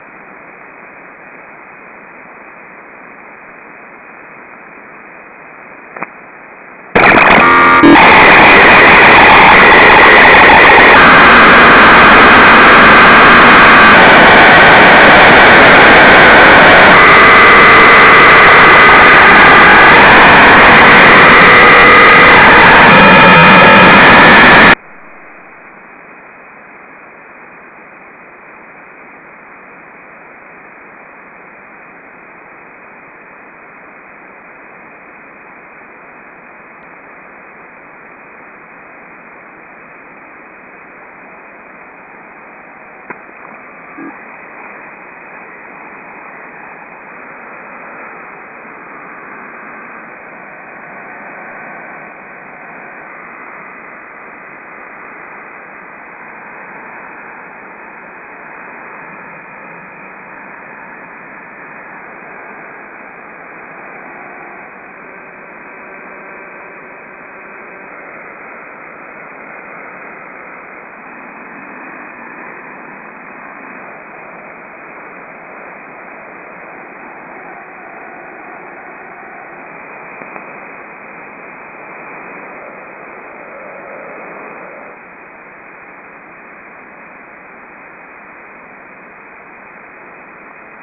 I made a recording with and without the filter in place.  The first part of the recording is the normal band noise on 40 meters.  In the next part of the recording, the filter is NOT in.  You can then hear the lamp turn on and the RFI subside a bit as the lamp warms up.  Next you hear 40 meter band noise again. The third part of the recording is with the lamp on and the filter IN.  You have to listen carefully for the RFI.   Finally, the last part of the recording is with the lamp turned off and the filter IN.  You can barely hear the difference between the filtered RFI and the normal band noise.
Grow Light Ballast Filter Out in Comparison.wav